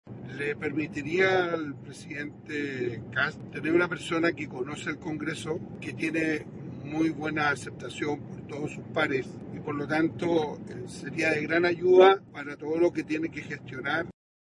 Para el senador Rafael Prohens (RN), García Ruminot sería un gran aporte dentro del círculo de confianza de Kast, ya que conoce las labores legislativas y es valorado dentro del Parlamento.